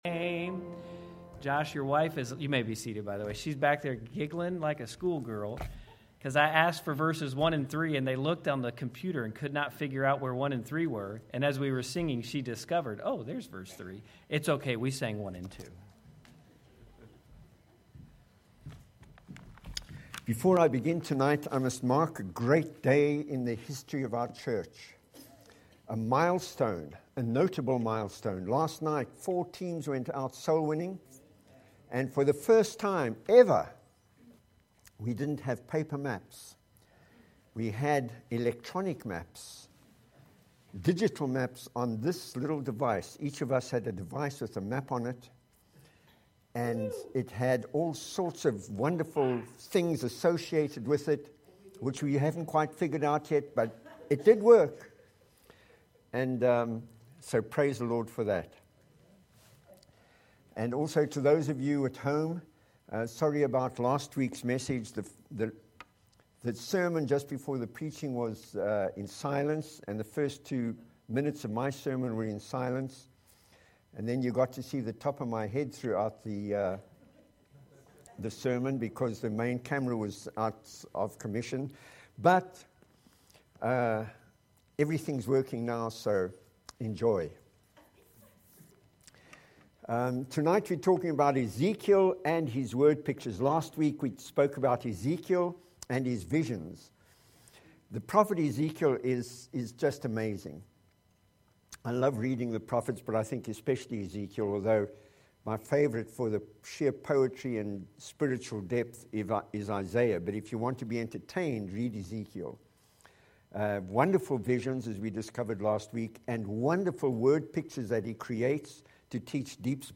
Sermons by Bluegrass Baptist Church